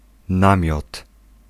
Ääntäminen
IPA : /tɛnt/